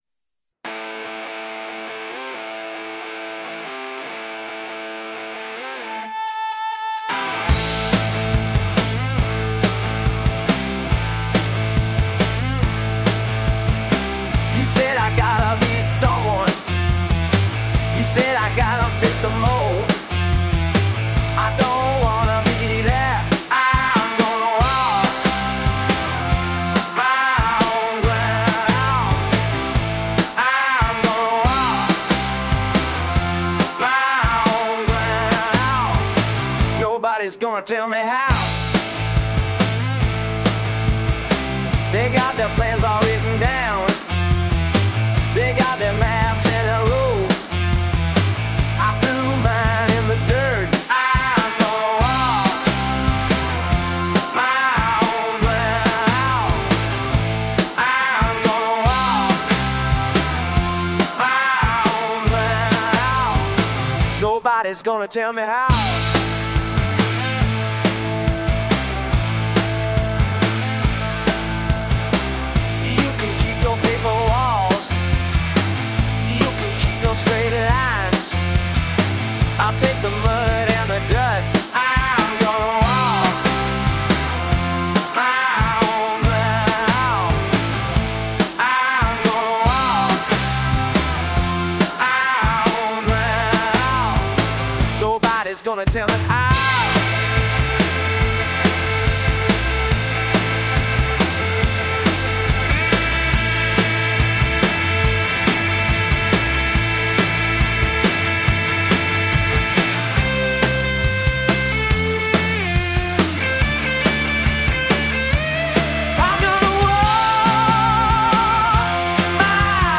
Showing all tracks in the "Alternative" category.